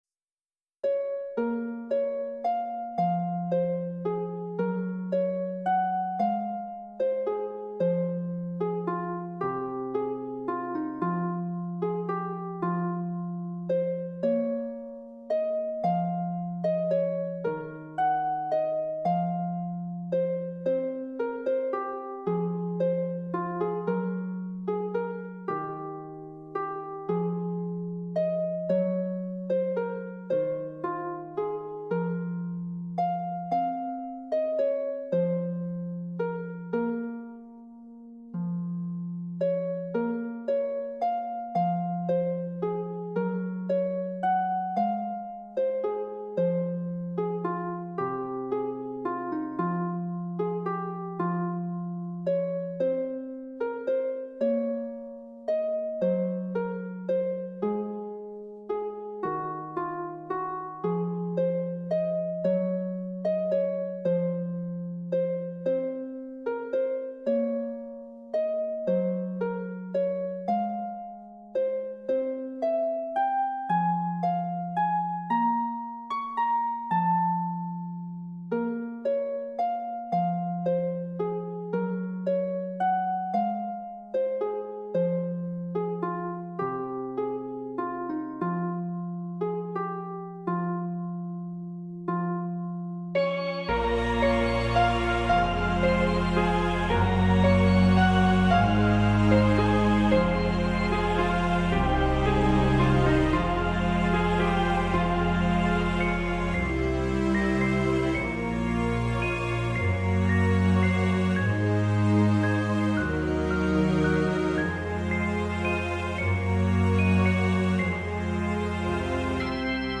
I STILL love this harp melody